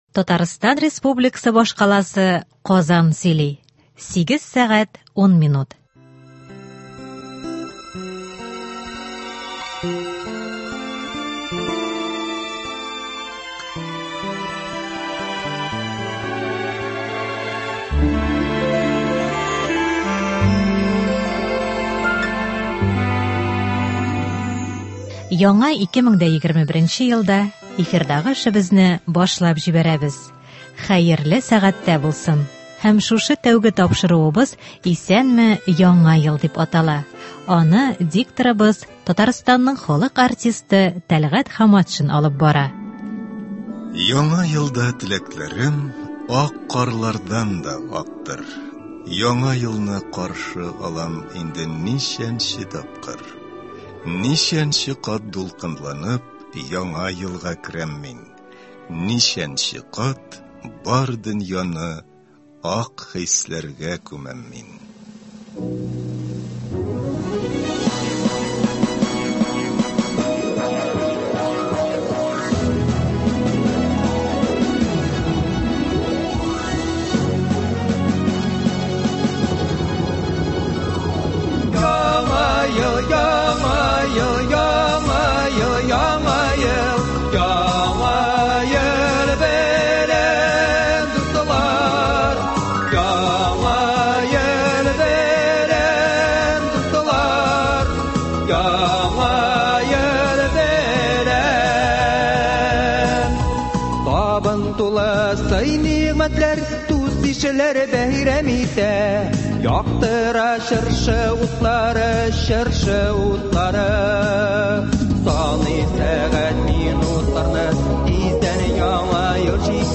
Музыкаль программа (01.01.21)